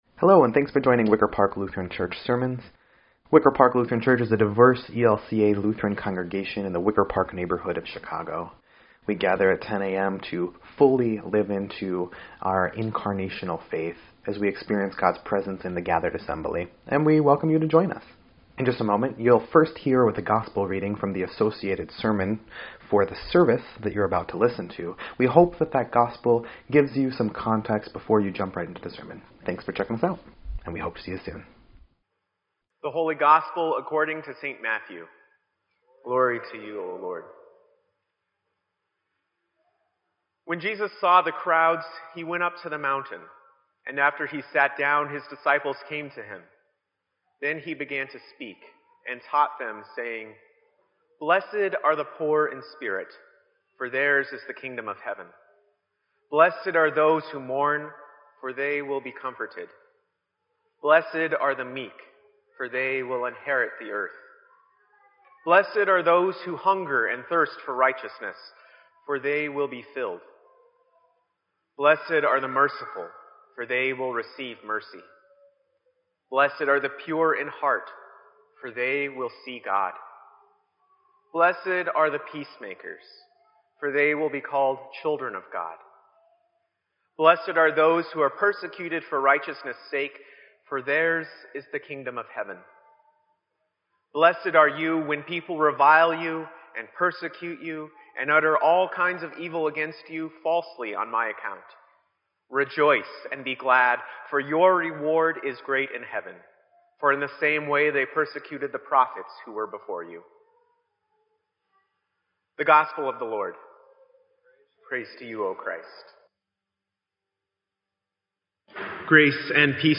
Sermon_1_29_17.mp3